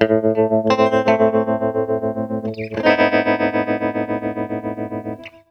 SUSPENSE.wav